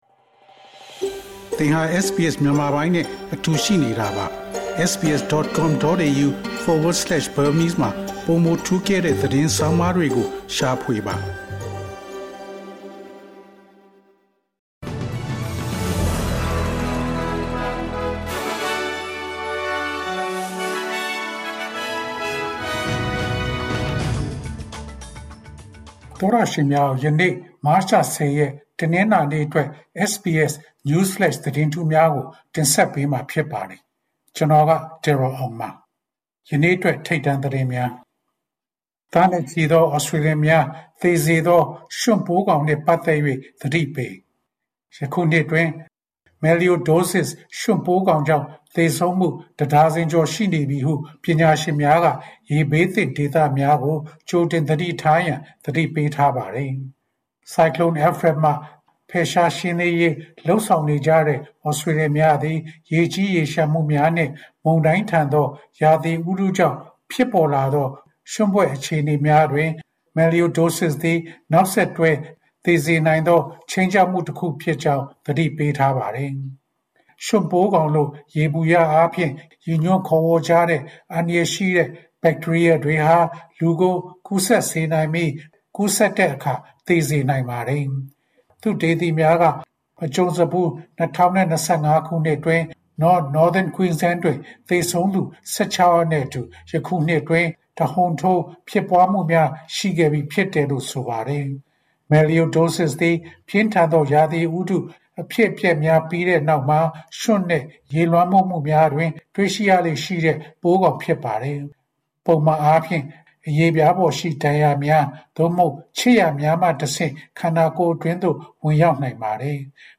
ALC: ၂၀၂၅ ခုနှစ် မတ်လ ၁၀ ရက်, SBS Burmese News Flash သတင်းများ။ 06:21 Tropical Cyclone Alfred hit Queensland.